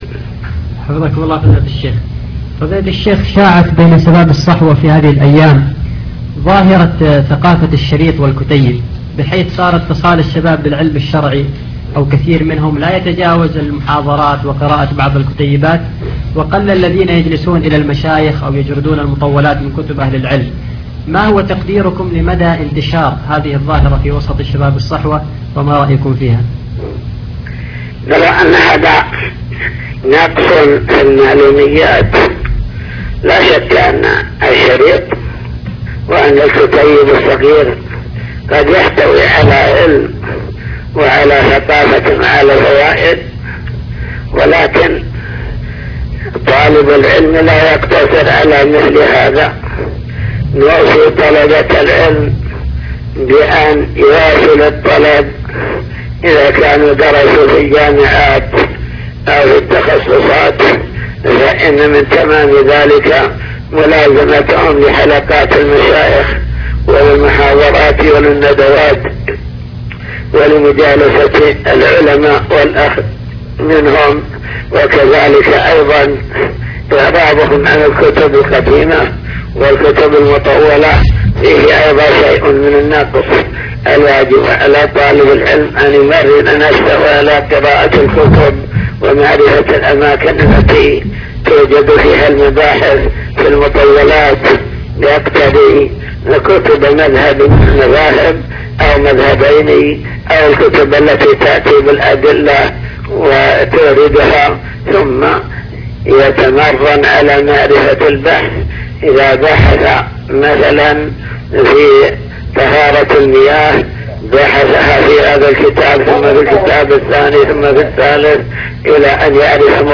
حوار هاتفي